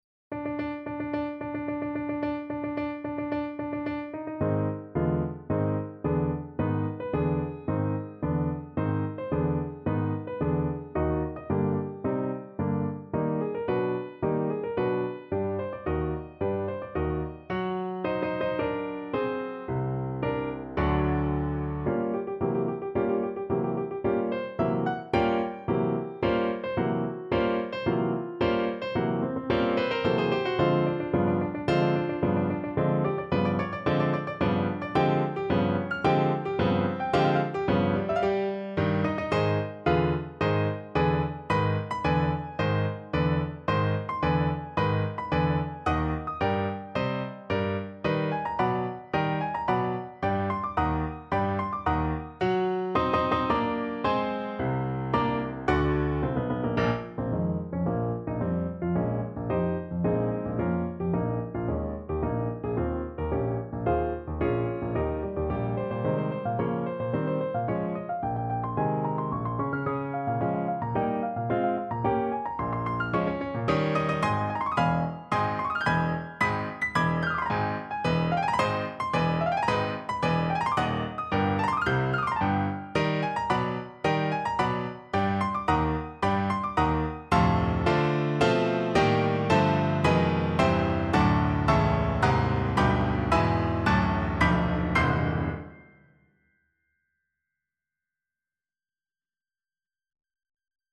No parts available for this pieces as it is for solo piano.
4/4 (View more 4/4 Music)
~ = 110 Tempo di Marcia
Piano  (View more Advanced Piano Music)
Classical (View more Classical Piano Music)